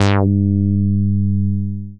BASS05.WAV